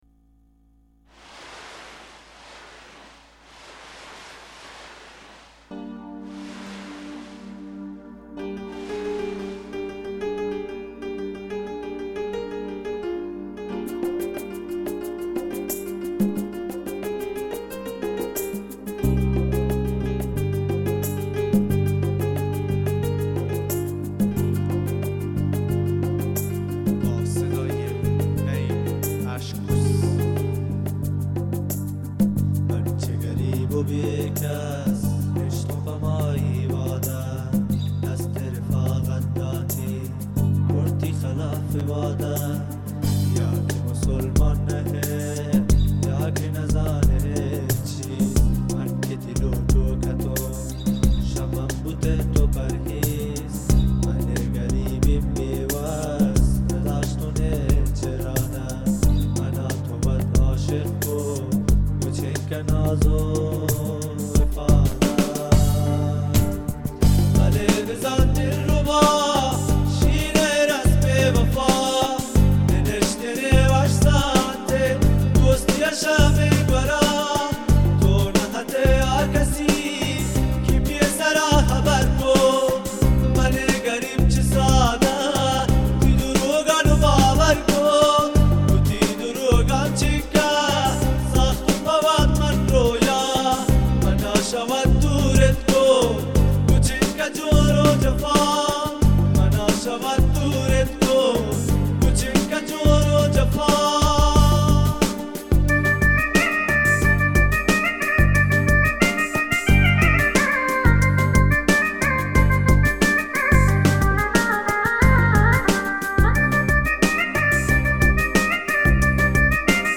اهنگ بلوچی